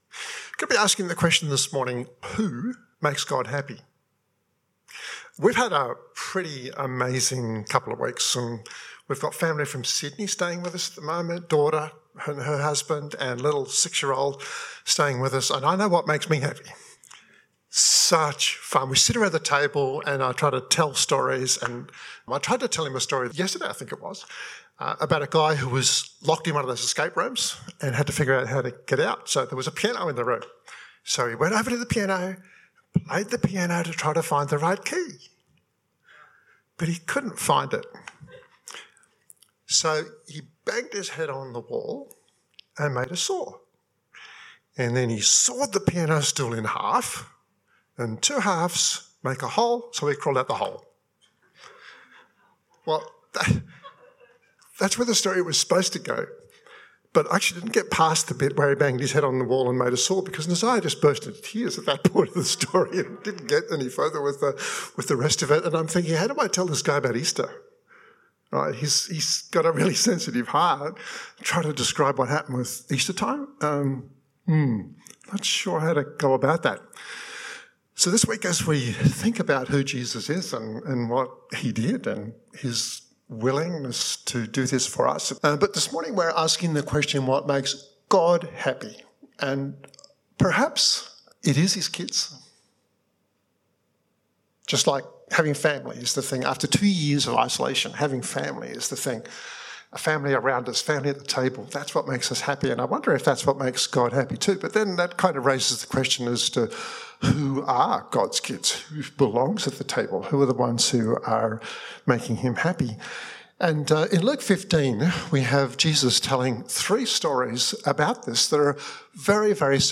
This 25-minute podcast was recorded at Riverview Joondalup 2022-04-10.